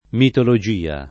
[ mitolo J& a ]